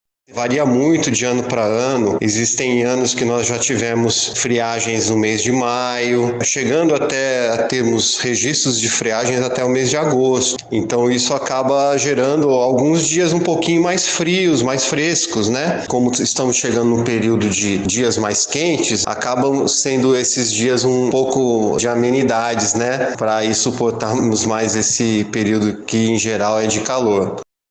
SONORA03_AMBIENTALISTA.mp3